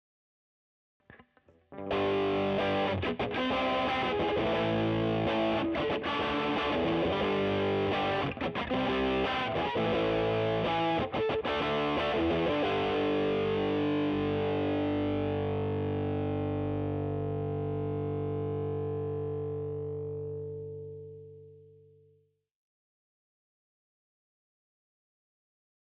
Demo Audio realizzate presso Trees Music Studio di Cava de’Tirreni
Chitarra Elettrica MD441 CLEAN Focusrite Red 8Pre
Chitarra Elettrica – Gibson Les Paul Standard
Amplificatore per Chitarra – Fender Deville
Microfono – Sennheiser MD441 U